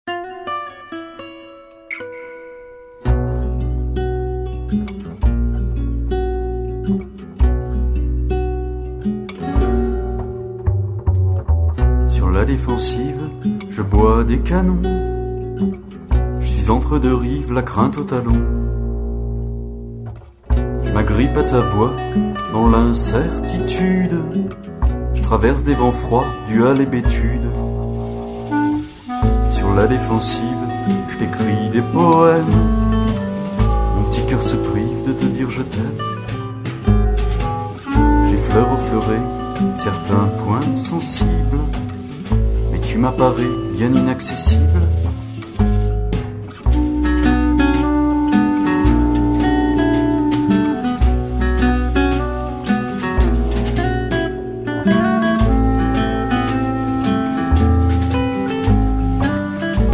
chanson francaise